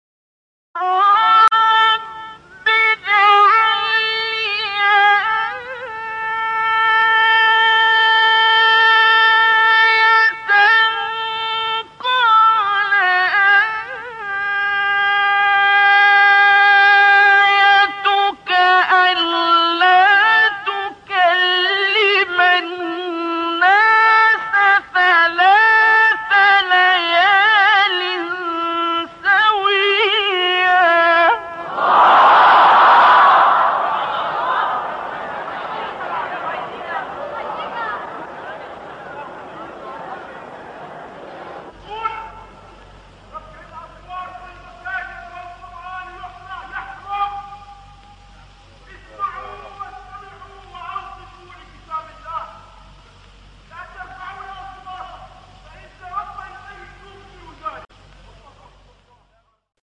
سوره : مریم آیه: 10 استاد : عبدالباسط محمد عبدالصمد مقام : بیات قبلی بعدی